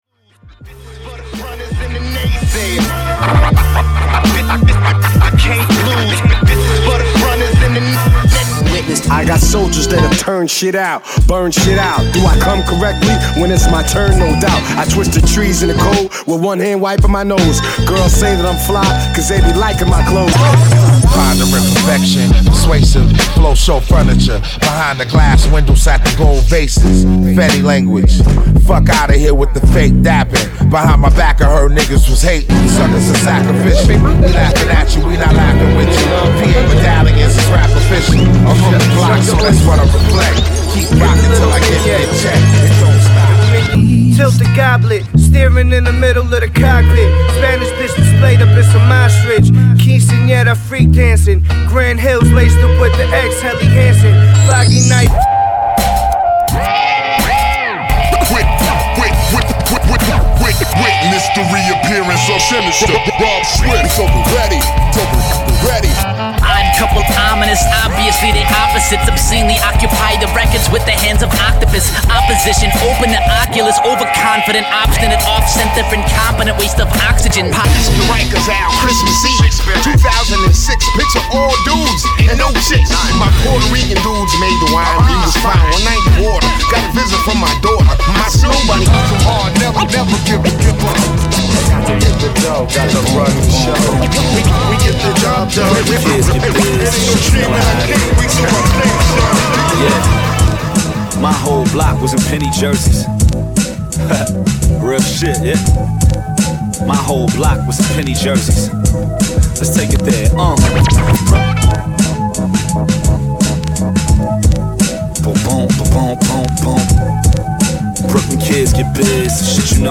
ド真ん中、ビートとラップが直球勝負する序盤。
2020年はサンプリングサウンドが一つのポイントになりそうな予感！？
＊試聴はダイジェストです。